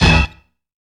GUITARHORN.wav